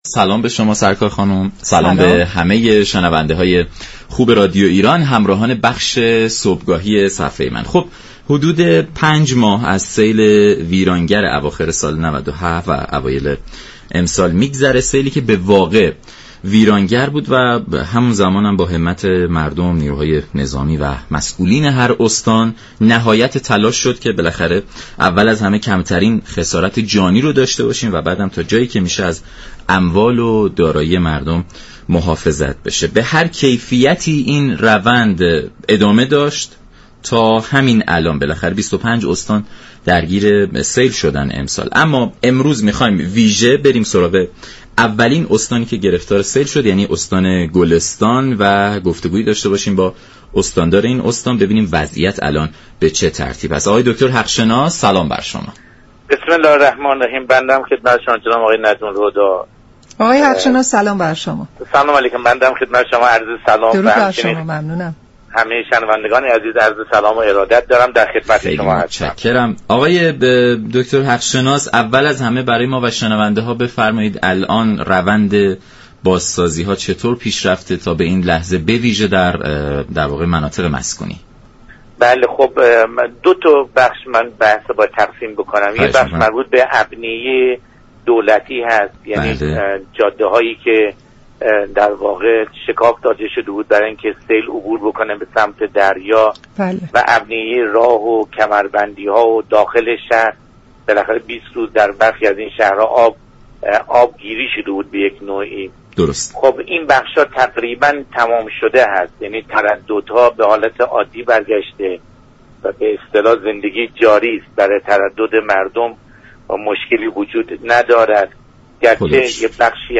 دكتر حق شناس استاندار گلستان در گفت و گو با رادیو ایران درباره روند بازسازی ها در پی سیل اخیر گفت: تا پایان هفته تعمیر 10 هزار و 500 واحد مسكونی به اتمام خواهد رسید.